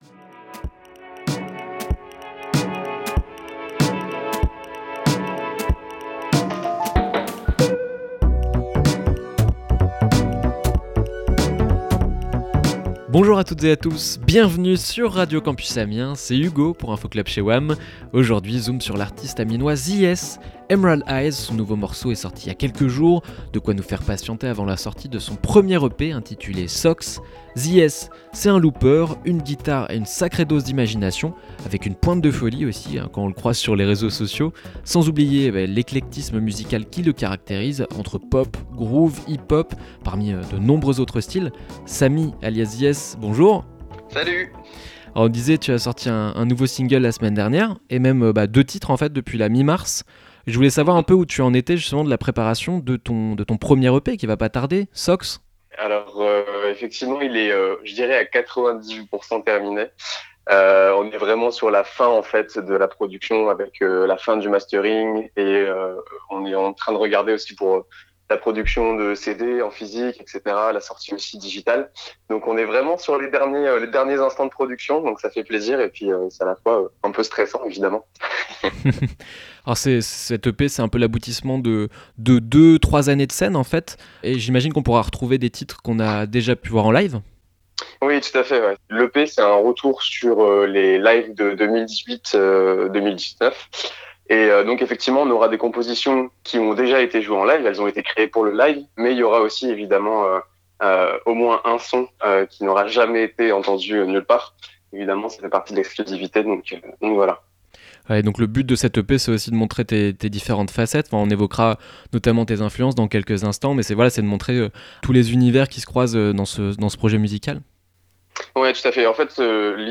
Info Club Sandwich évolue pendant le confinement pour devenir Info Club Chez Oim, émission enregistrée en appartement, à retrouver chaque jour du lundi au vendredi à 12h et 18h !